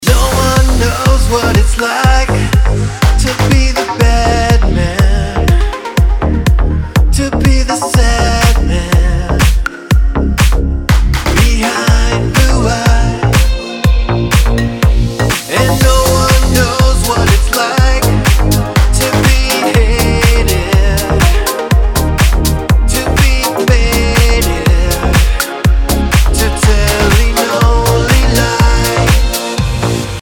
мужской вокал
dance
Electronic
Ремикс